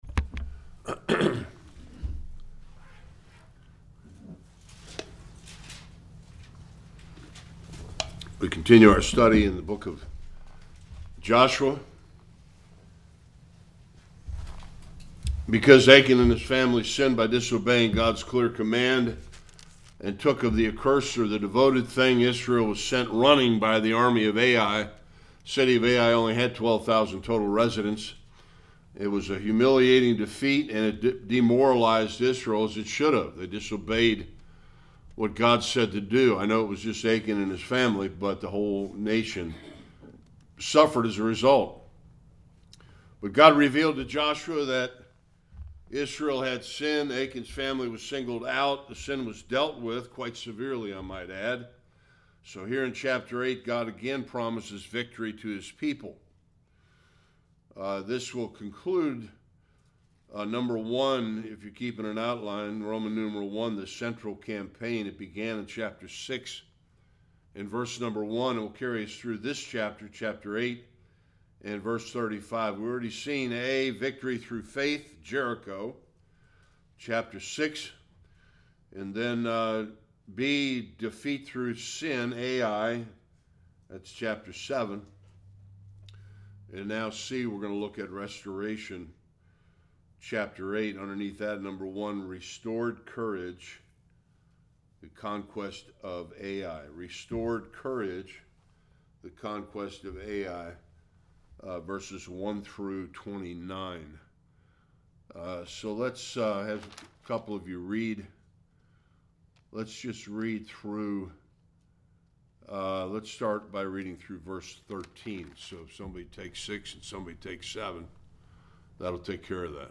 1-35 Service Type: Sunday School Israel’s victory at Ai and what we can learn from it.